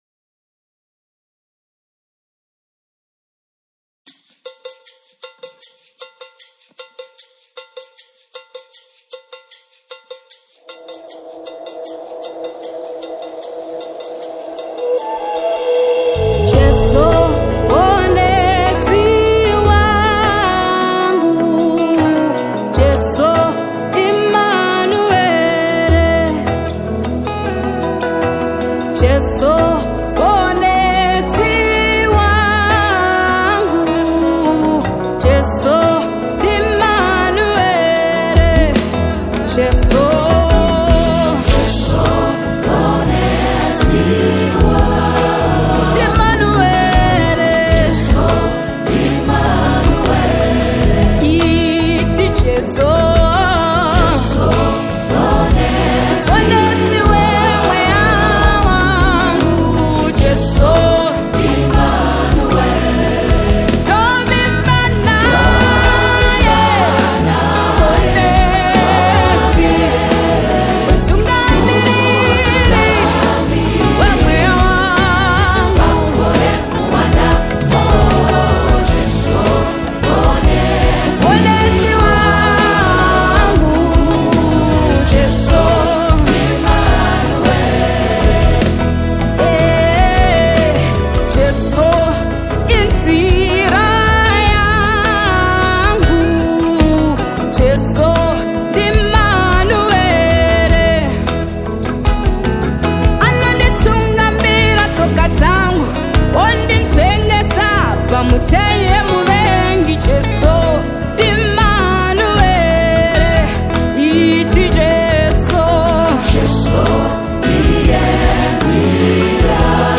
International Gospel Songs
a powerful worship song to uplift and inspire you.